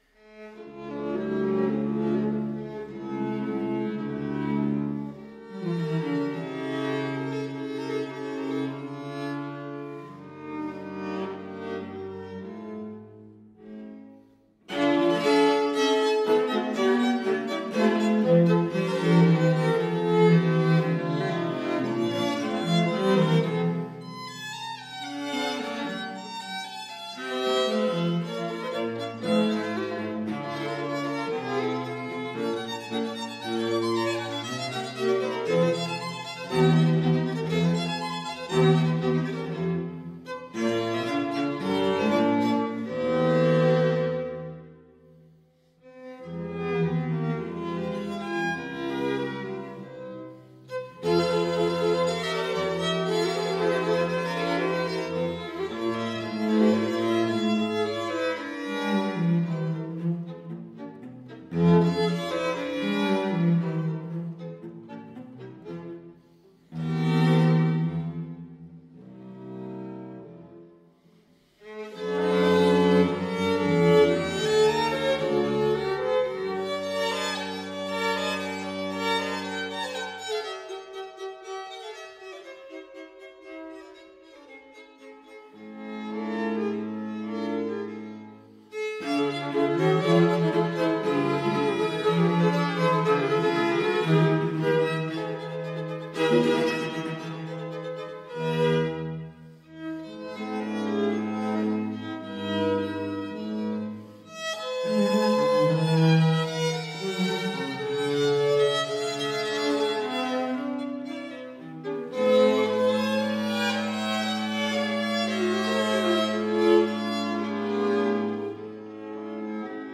Soundbite Movt 1